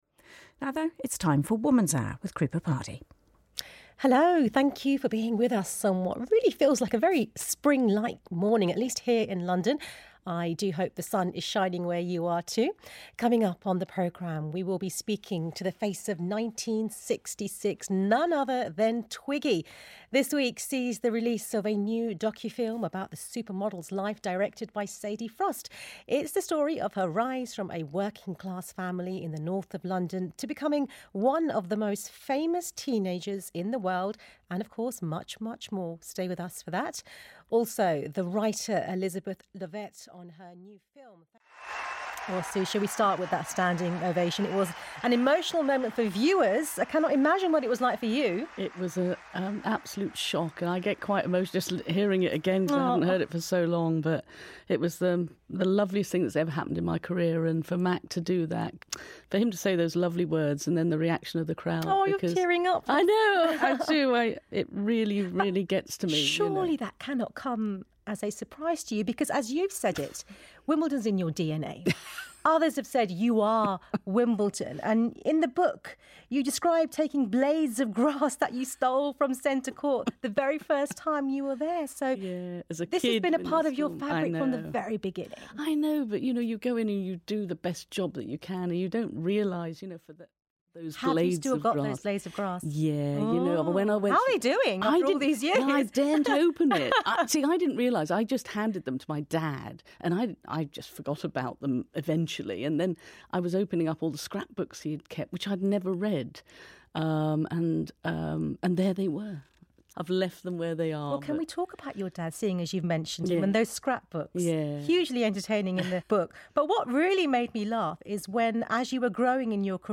Broadcaster
showreel-2025.mp3